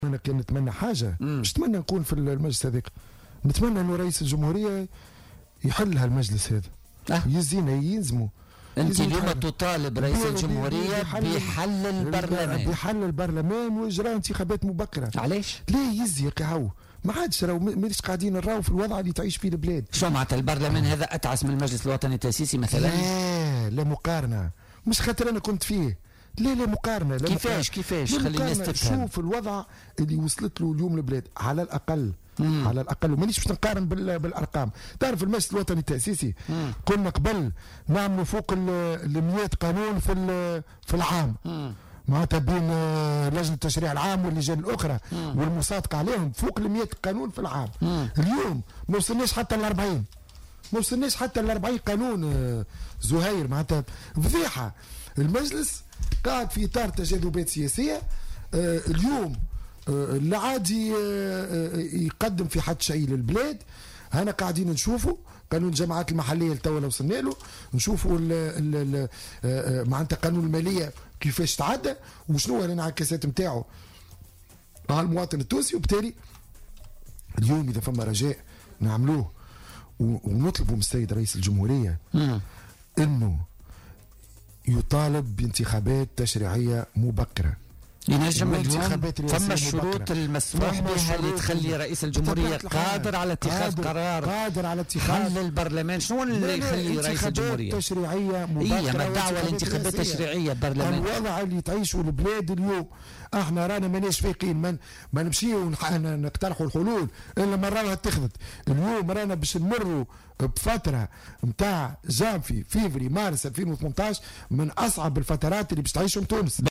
وأضاف البارودي، ضيف برنامج "بوليتيكا" اليوم أنه على رئيس الجمهورية الدعوة إلى انتخابات رئاسية وتشريعية مبكرة لإخراج البلاد من أزمتها، وفق تعبيره.